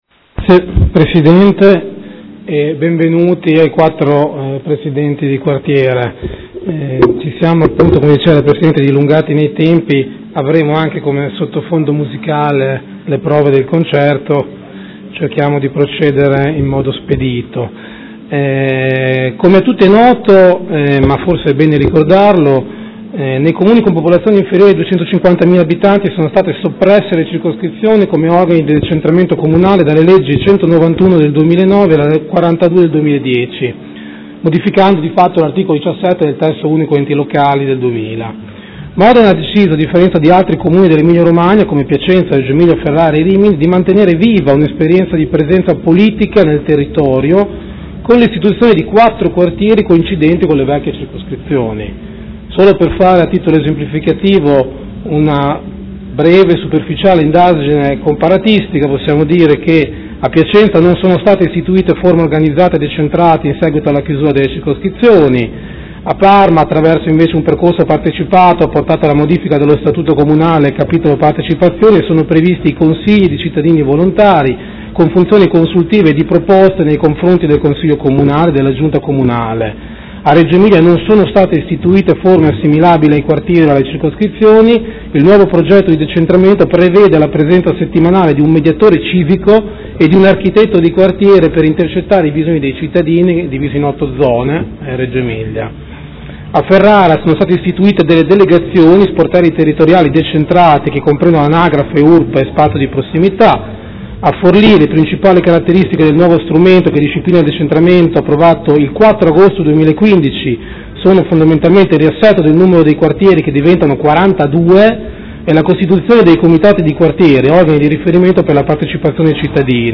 Seduta del 5/5/2016 Audizione dei Presidenti di Quartiere.